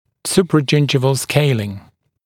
[ˌsuprəˈdʒɪndʒɪvl ˈskeɪlɪŋ][ˌсупрэˈджиндживл ˈскейлин]удаление зубного камня и налета на поверхности зуба у десневой кромки